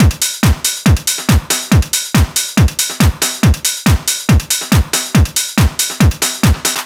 NRG 4 On The Floor 003.wav